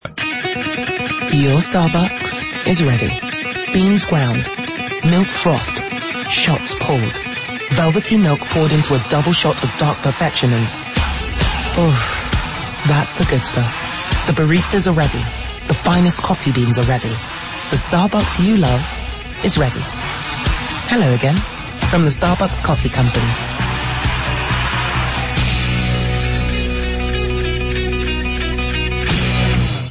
From the opening note, it pulses with a rhythmic intensity that mirrors the jolt of a fresh espresso shot. The music isn’t just background – it’s propulsion.
The script stays focused on the product benefit and delivers it with pace and positivity.
And the voiceover? Warm, conversational, and brimming with the kind of friendly urgency that invites, not interrupts.
Music, message and mood are perfectly aligned.